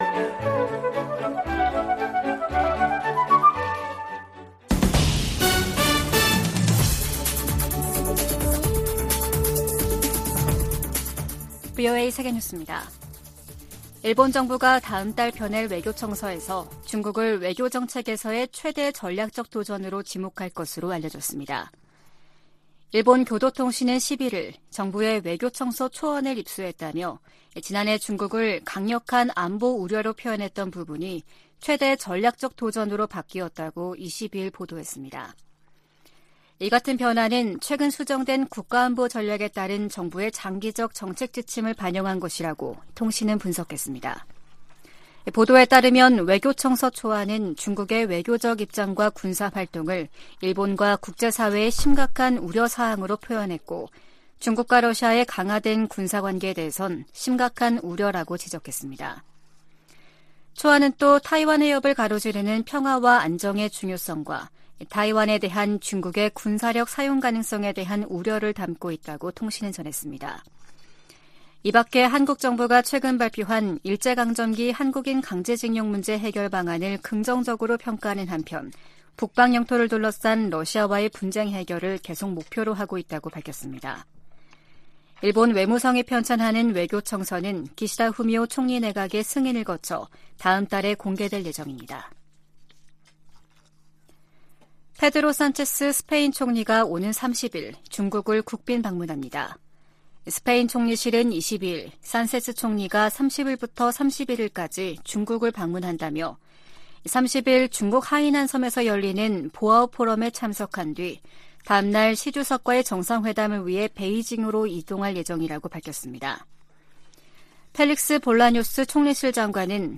VOA 한국어 아침 뉴스 프로그램 '워싱턴 뉴스 광장' 2023년 3월 24일 방송입니다. 백악관은 북한의 핵 공격이 임박했다는 징후는 없지만 최대한 면밀히 주시하고 있다고 밝혔습니다. 북한은 국제사회의 비핵화 요구를 핵 포기 강요라며 선전포고로 간주하고 핵으로 맞서겠다고 위협했습니다. 미국 국무부가 한국 정부의 최근 독자 대북제재 조치에 환영의 입장을 밝혔습니다.